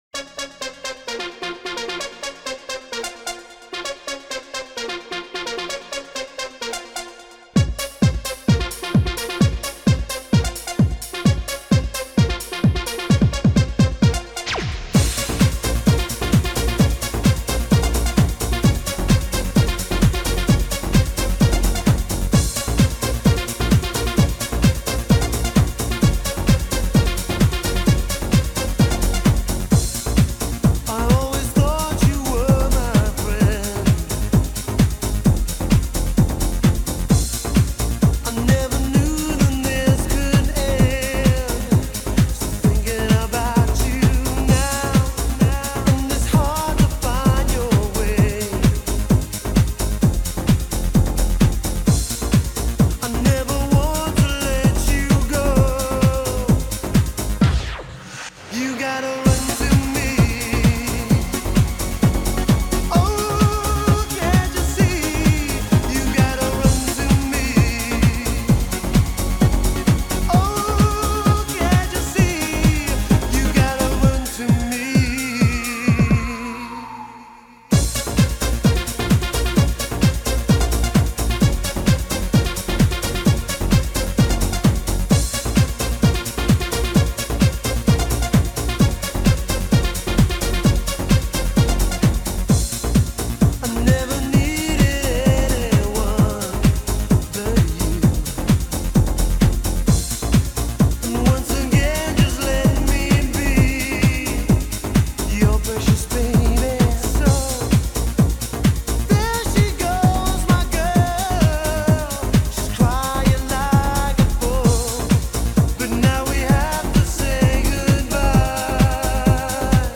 an electrifying mix by Miami’s DJ & VJ